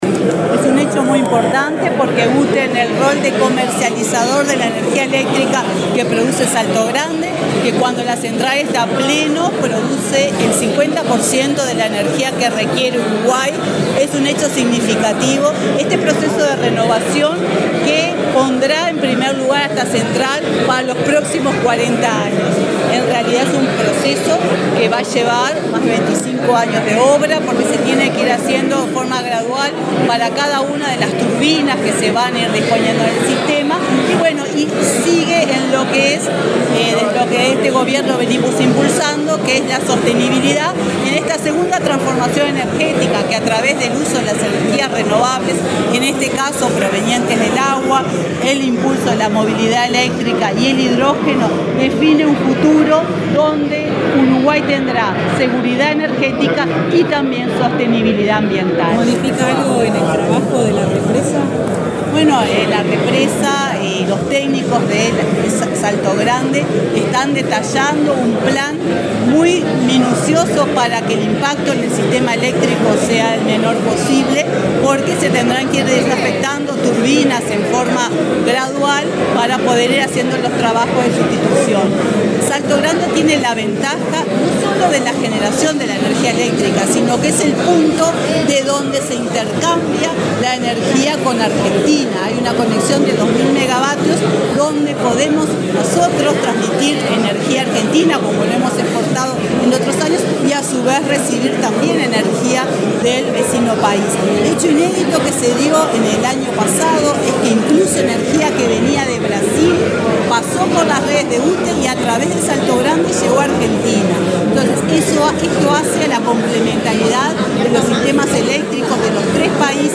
Declaraciones de la presidenta de UTE, Silvia Emaldi
Declaraciones de la presidenta de UTE, Silvia Emaldi 20/04/2023 Compartir Facebook X Copiar enlace WhatsApp LinkedIn La presidenta de la UTE, Silvia Emaldi, participó, en la Torre Ejecutiva, en la presentación de la segunda etapa del proyecto de renovación y modernización del complejo hidroeléctrico de Salto Grande. Luego dialogó con la prensa.